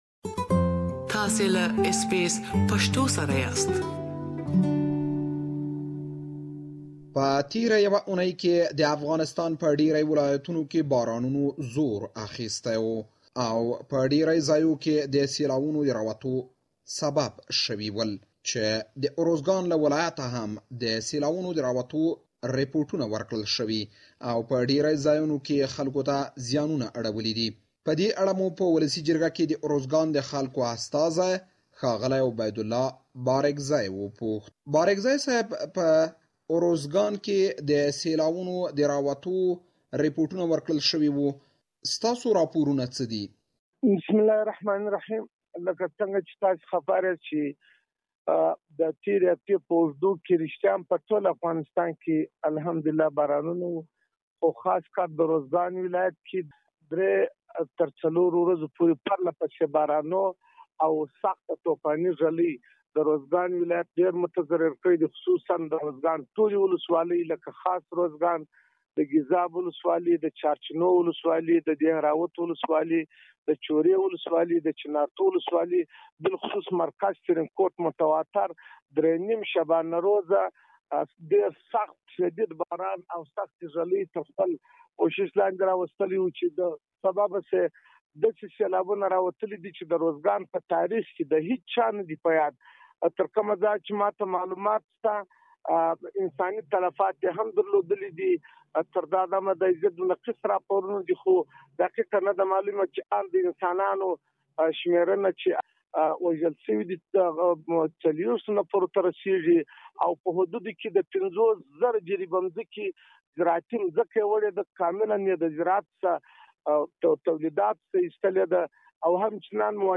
We have interviewed Uruzgan's Member of Parliament on the recent losses and assistance; please listen to the full interview here.